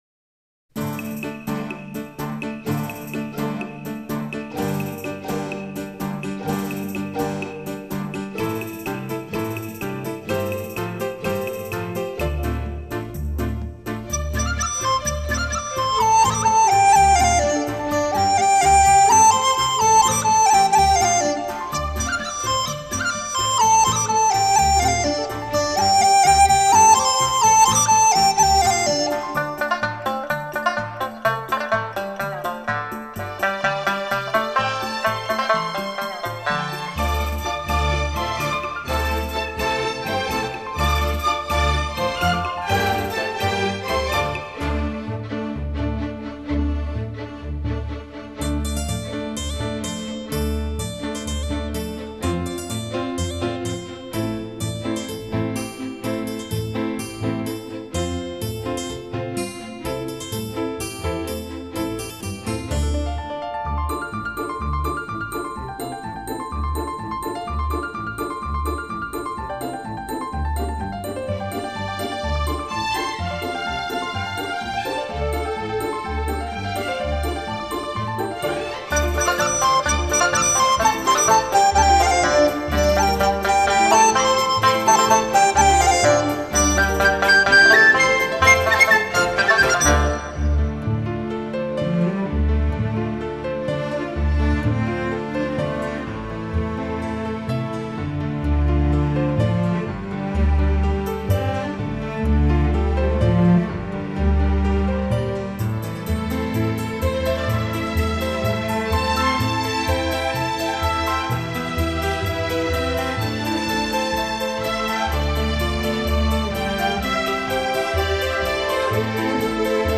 专辑类型：纯音乐
本辑是由黑龙江民歌改编的轻音乐。以中国民族乐器为主奏，辅以
西洋管弦乐器及电声乐器。
最新数码系统录制，音响爆棚，旋律悦耳，您会从音乐中感受到